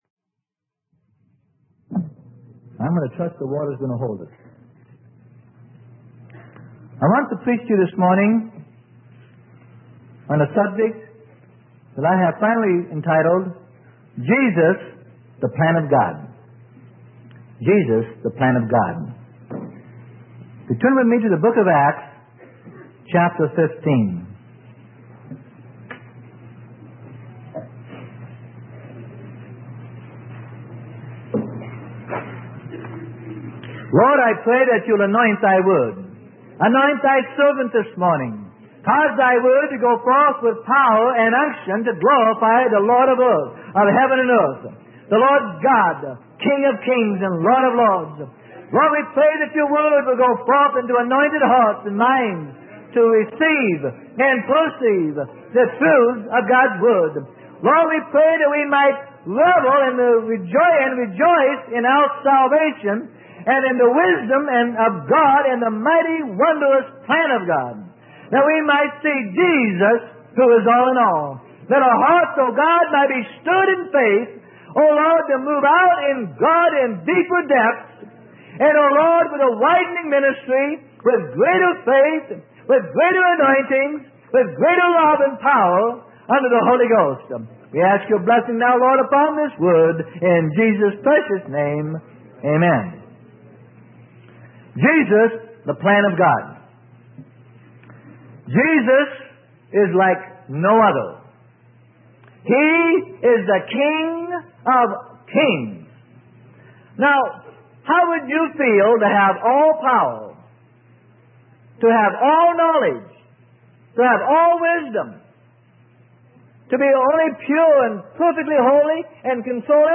Sermon: Jesus the Plan of God - Freely Given Online Library